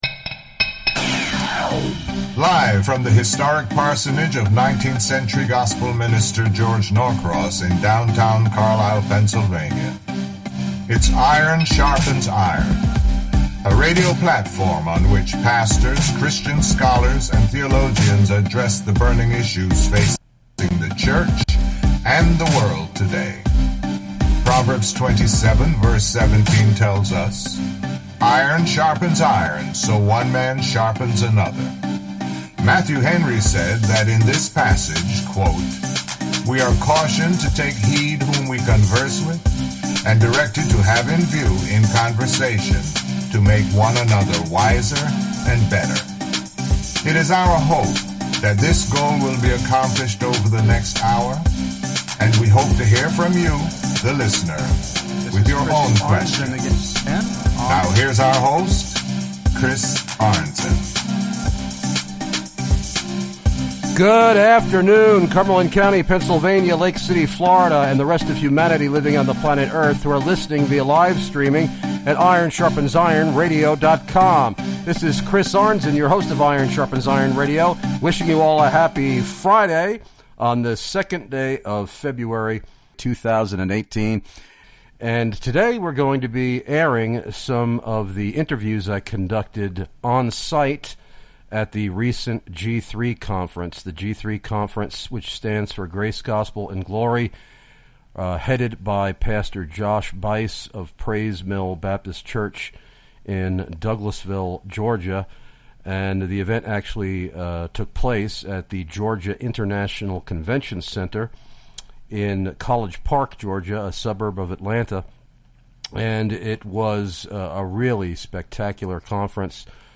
Interviews recorded LIVE at the 2018 G3 CONFERENCE!!!
These interviews were conducted on-site from the Iron Sharpens Iron Radio booth in the exhibition hall of the Georgia International Convention Center in Atlanta.